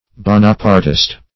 Search Result for " bonapartist" : The Collaborative International Dictionary of English v.0.48: Bonapartist \Bo"na*part`ist\, n. One attached to the policy or family of Bonaparte, or of the Bonapartes.